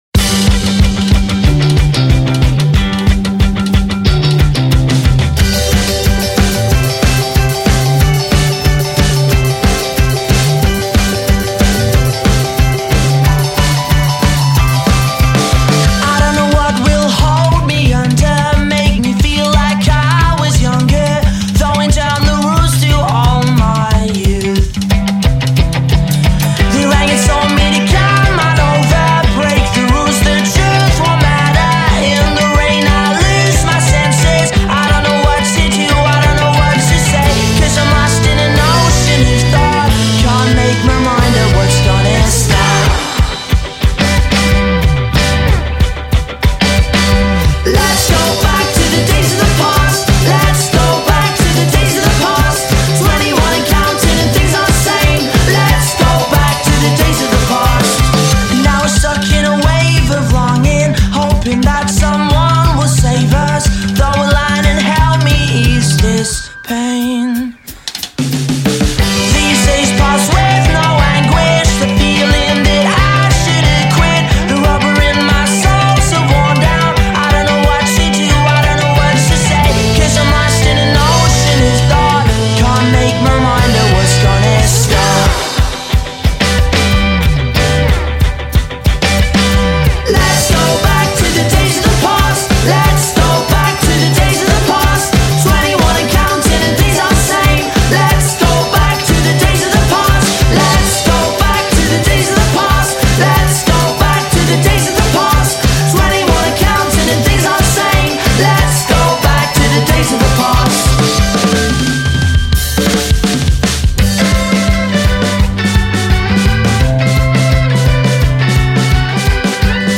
independent pop band